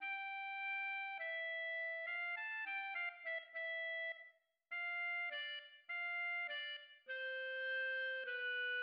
1 clarinette en La jouant en soliste
Le mouvement s'ouvre avec l'orchestre jouant le thème principal, tendre et délicat, repris par le soliste, élégant et malicieux.